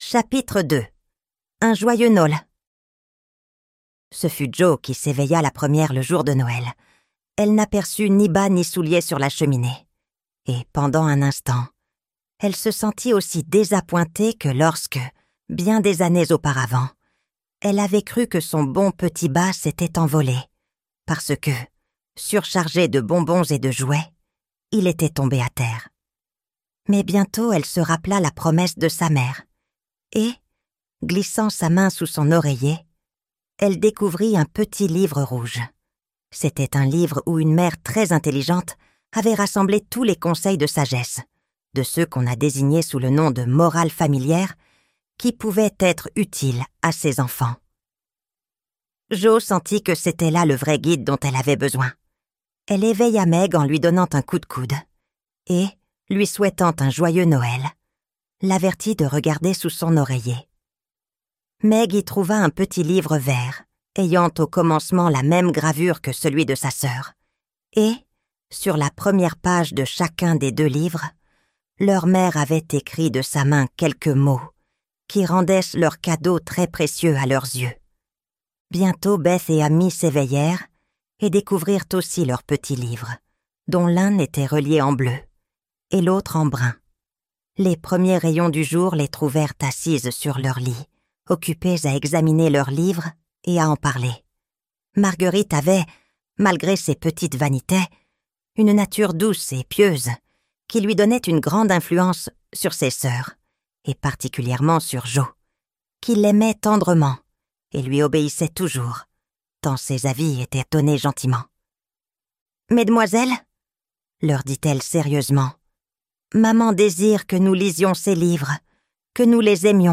Les Quatre Filles du docteur March - Livre Audio